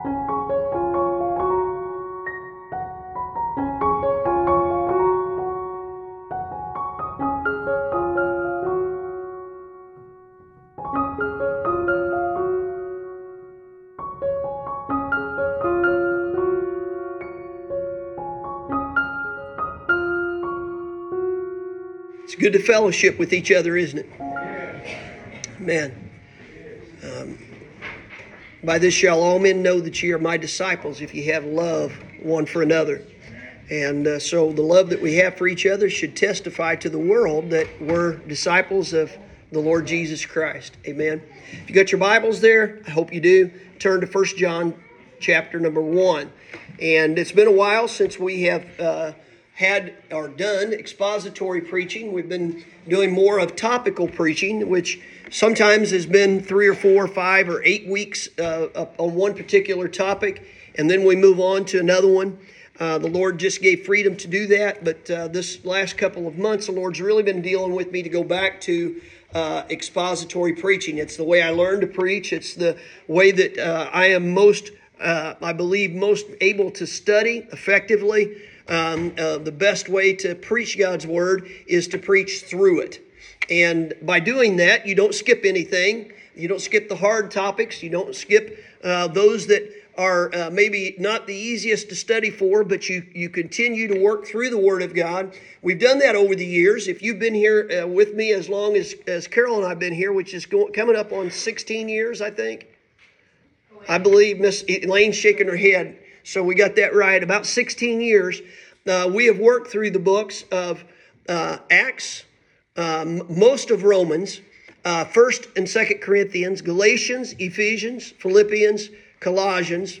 Sunday Morning – September 8th, 2024